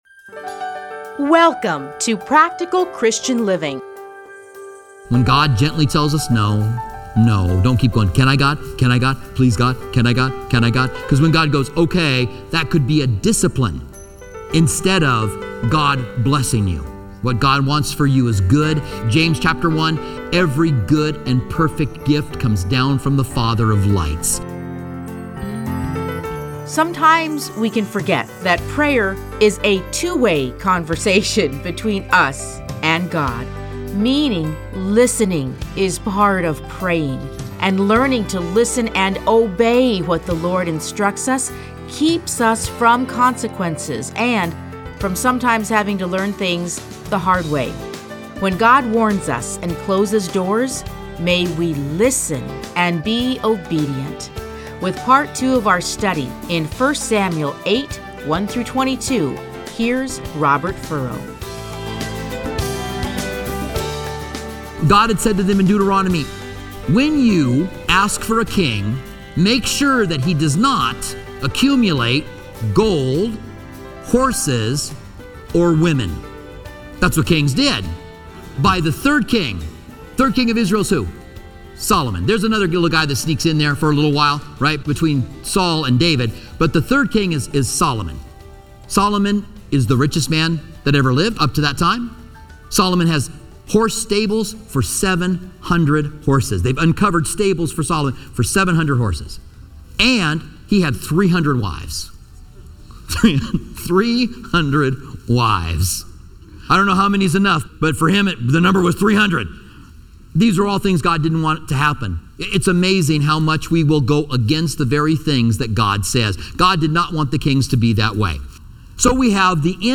Listen to a teaching from 1 Samuel 8:1-22.